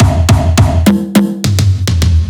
104 BPM Beat Loops Download